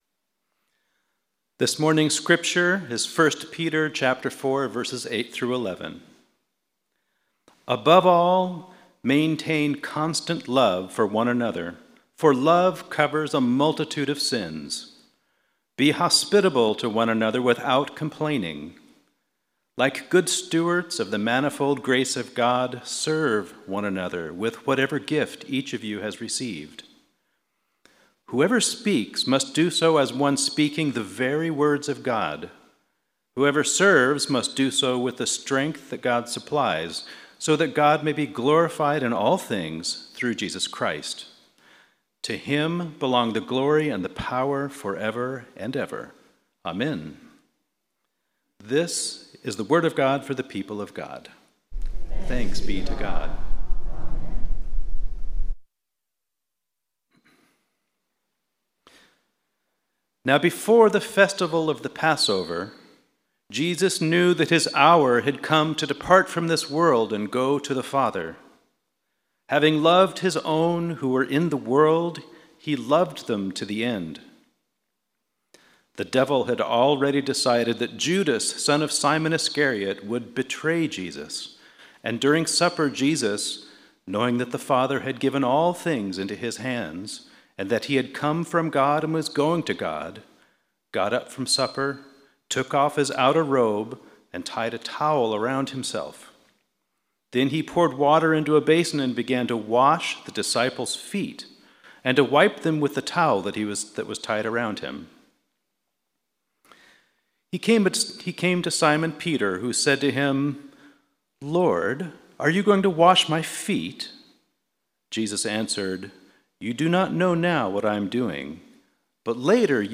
Sermon – Methodist Church Riverside